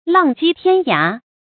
浪迹天涯 làng jì tiān yá 成语解释 浪迹：到处流浪、漂泊；天涯：形容极远的地方。
成语繁体 浪跡天涯 成语简拼 ljty 常用程度 常用成语 感情色彩 贬义成语 成语用法 动宾式；作谓语、定语；形容人生飘泊 成语结构 动宾式成语 产生年代 古代成语 成语正音 涯，不能读作“ái”。